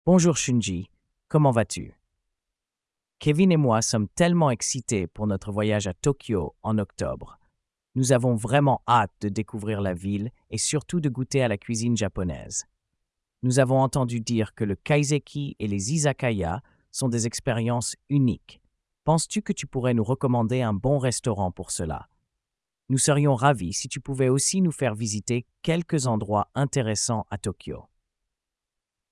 GPTsによる音声読み上げ（リスニング）
GPTsというツールを使えば、テキストを読み上げて音声ファイルに変換してもらうことができます。
発音は問題ないのですが、残念ながら男性の声になっています。